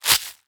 Flesh Ripped Fast Sound
horror